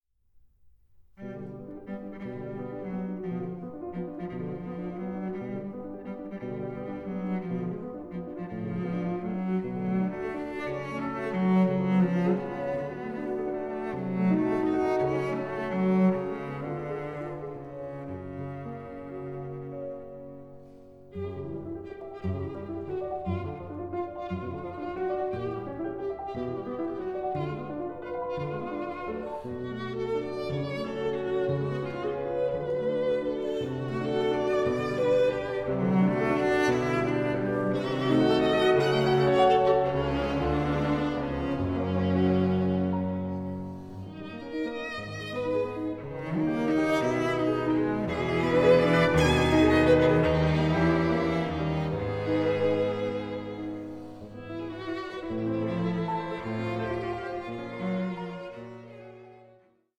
Allegro 06:24